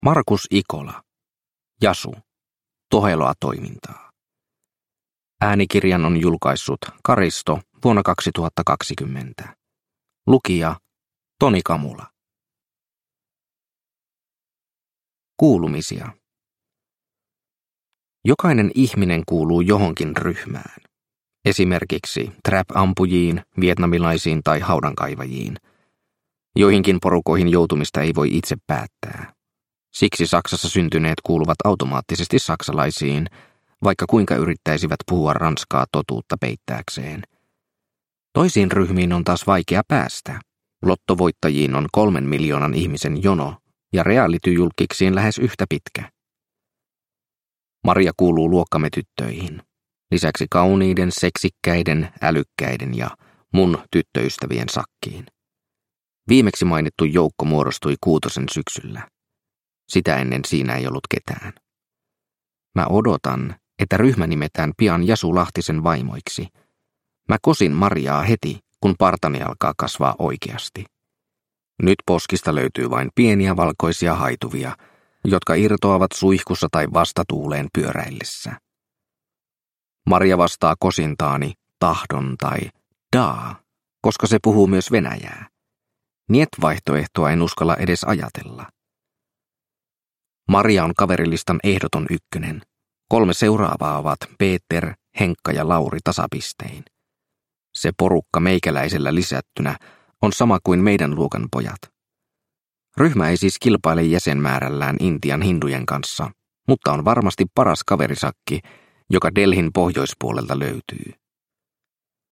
Jasu - Toheloa toimintaa – Ljudbok – Laddas ner